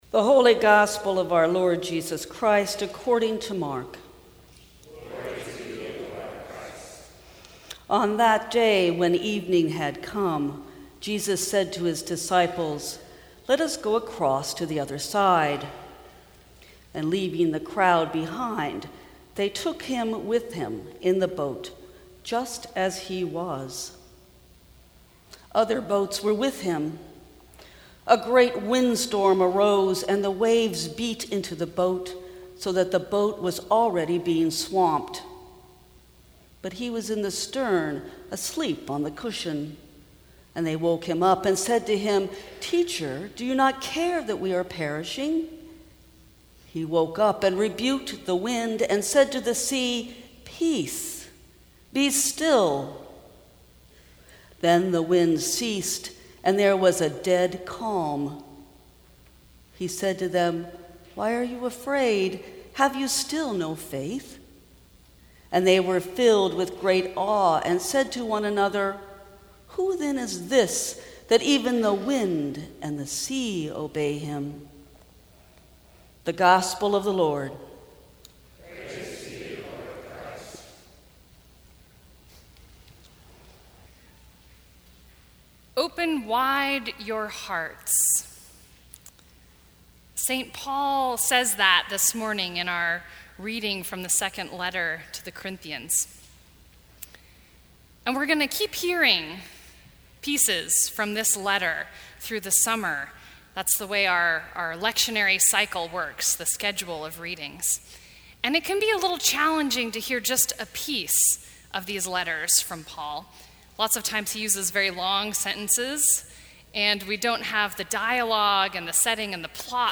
Sermons from St. Cross Episcopal Church Open Wide Your Hearts Jun 24 2018 | 00:17:31 1x 00:00 / 00:17:31 Subscribe Share Apple Podcasts Spotify Overcast RSS Feed Share Link Embed